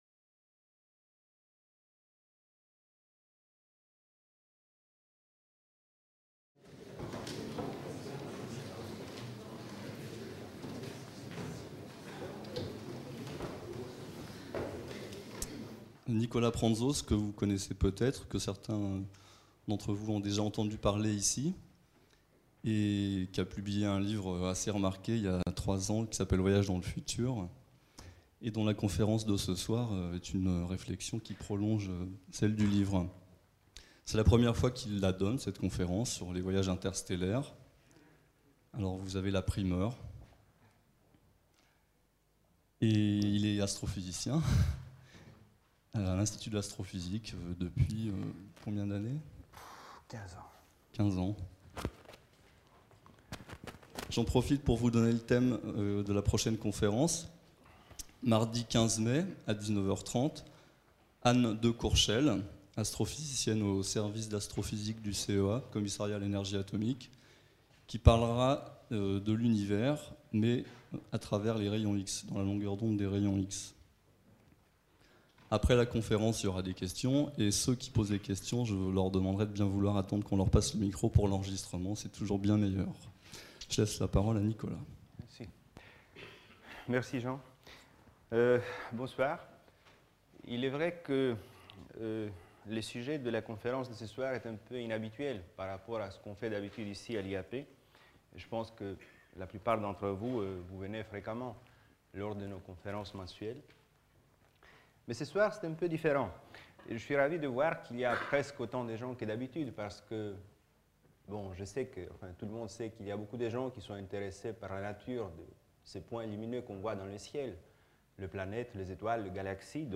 Conférence
à l'Institut d'astrophysique de Paris